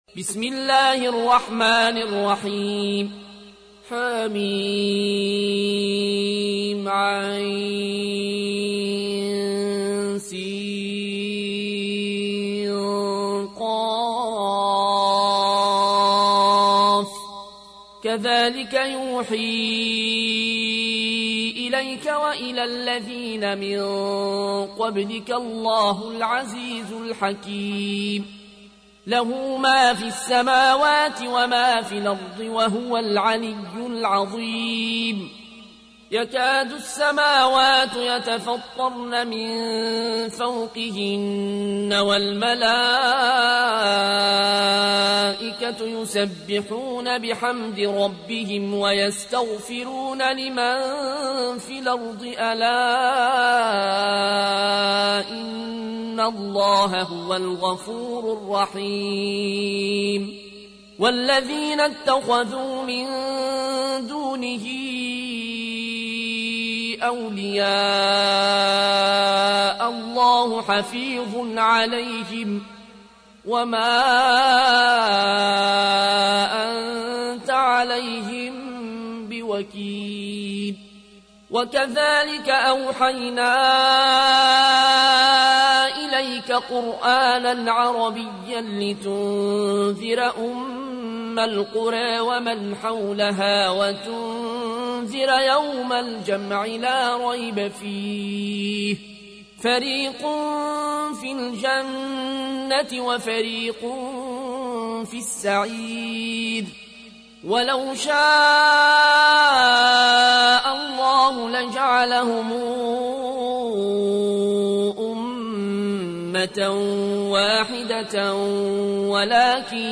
تحميل : 42. سورة الشورى / القارئ العيون الكوشي / القرآن الكريم / موقع يا حسين